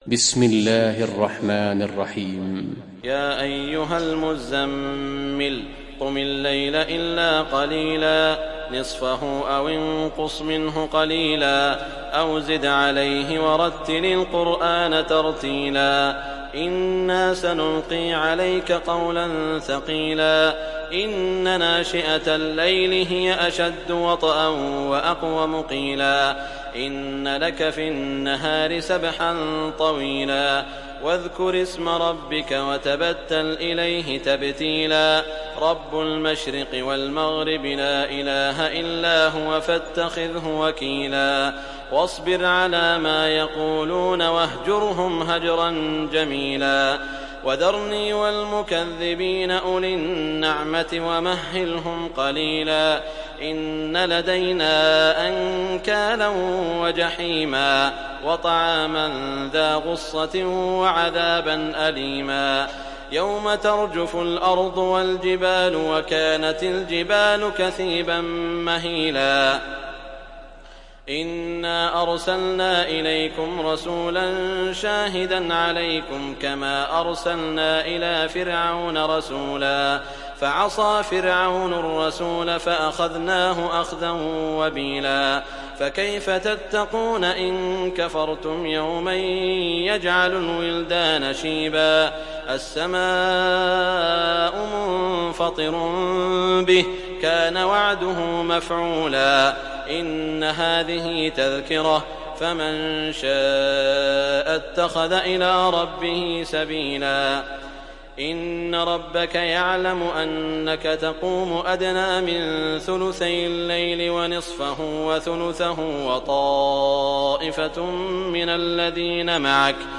Surah Al Muzzammil Download mp3 Saud Al Shuraim Riwayat Hafs from Asim, Download Quran and listen mp3 full direct links